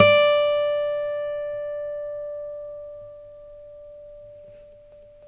Index of /90_sSampleCDs/Roland L-CDX-01/GTR_Steel String/GTR_18 String